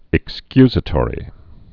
(ĭk-skyzə-tôrē)